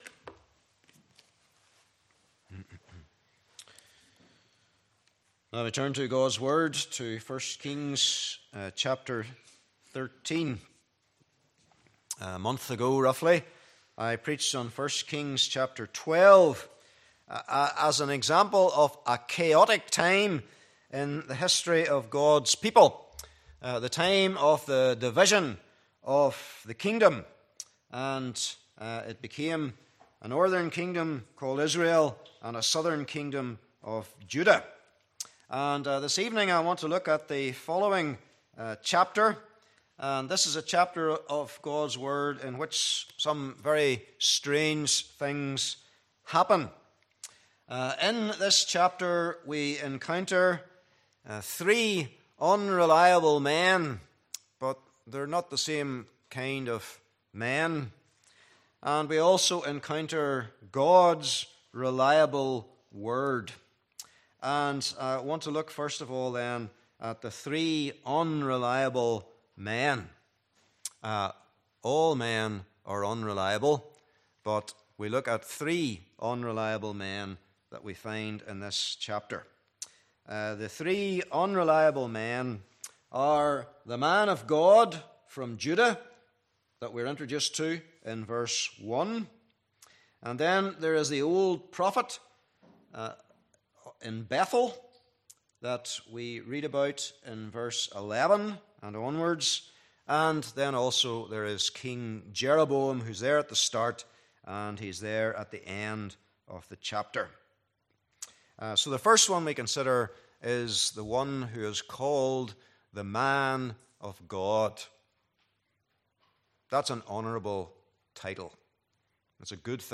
1 Kings 13:1-34 Service Type: Evening Service Bible Text